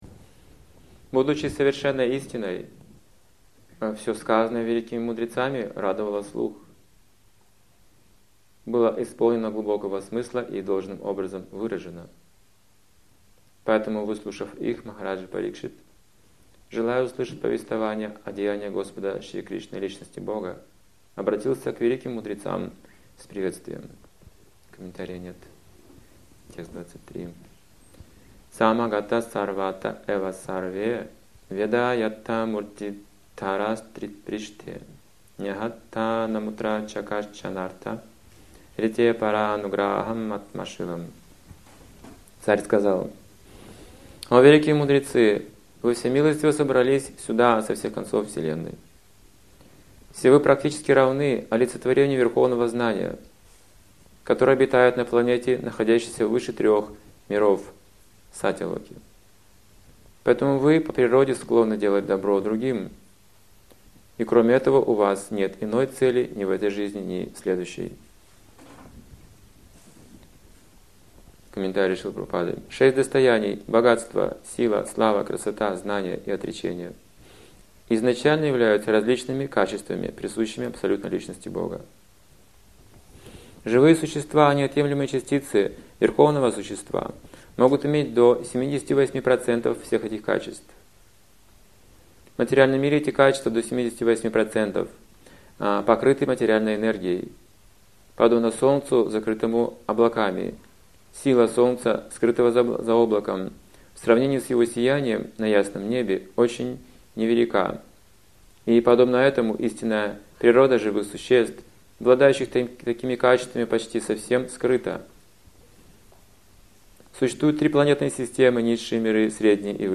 – Лекции и книги Александра Хакимова